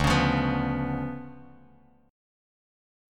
DmM13 chord